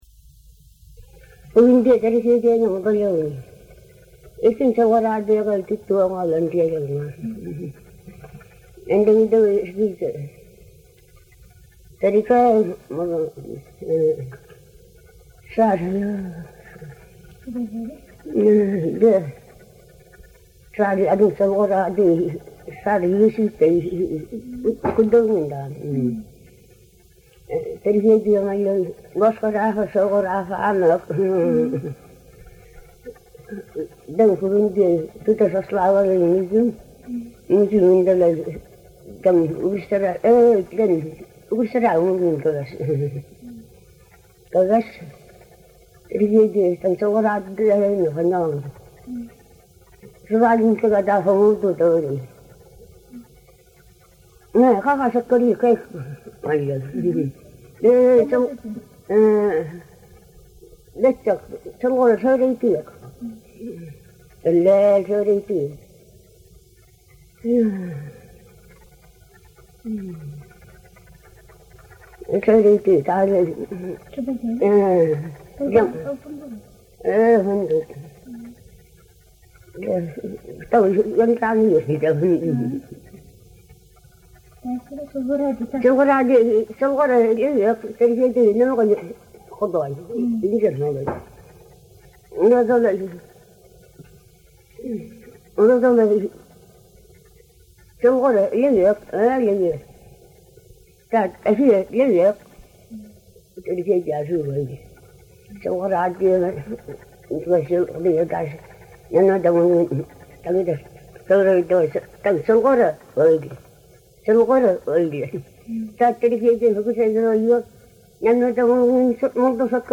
Online Kolyma Yukaghir Documentation